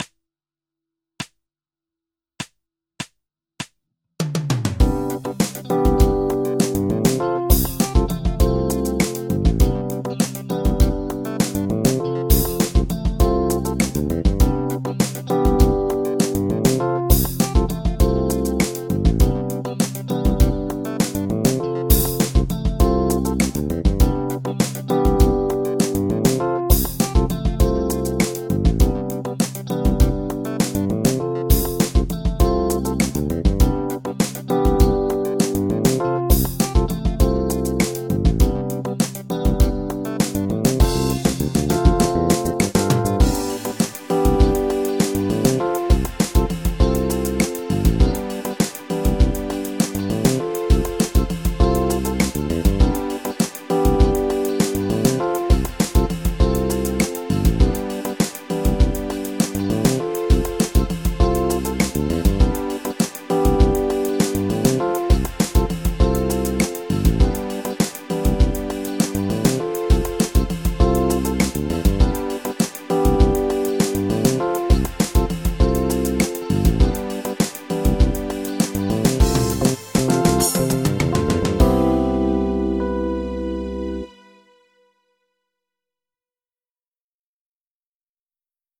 イオニアン・スケール ギタースケールハンドブック -島村楽器